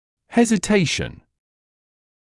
[ˌhezɪ’teɪʃn][ˌхэзи’тэйшн]колебание, сомнение; нерешительность